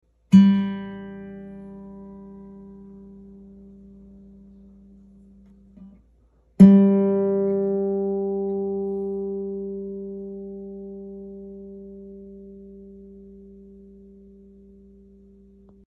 Guitar Tuner G String.